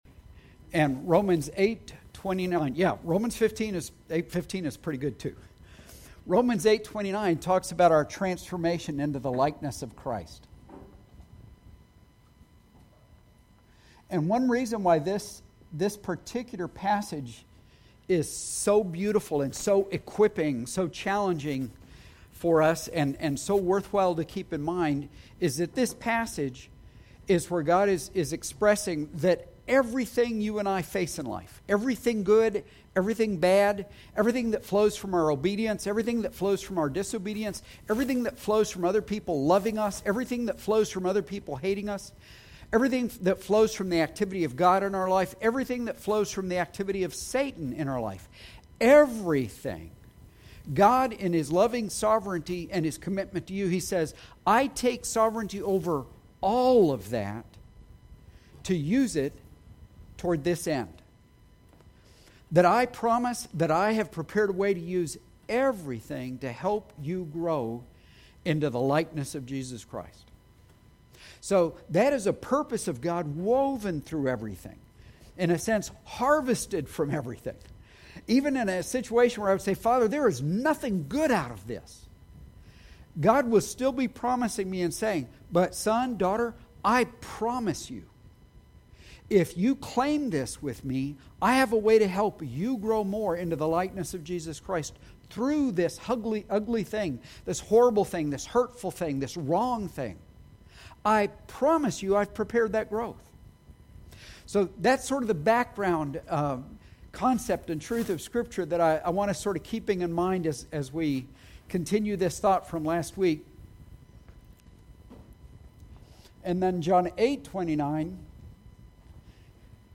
We apologize for technical difficulties at the 5 minute mark within this sermon.